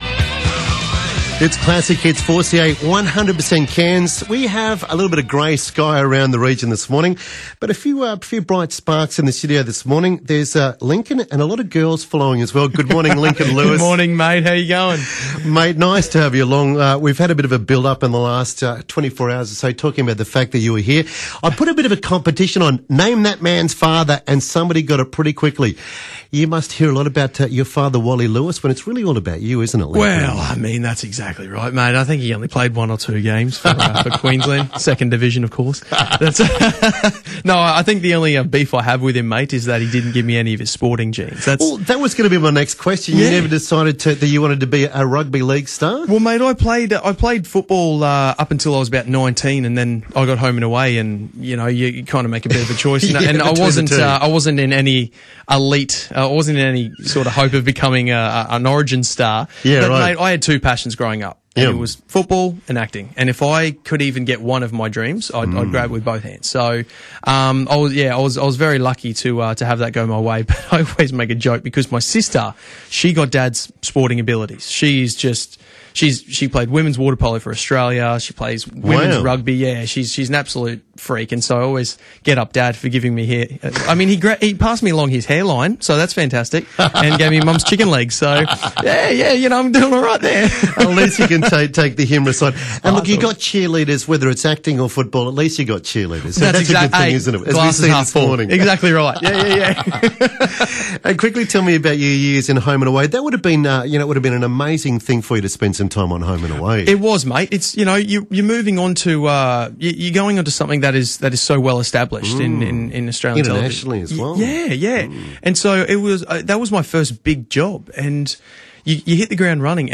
speaks with Lincoln Lewis about the new film Spin Out about love, friendship and being a kid in the country these days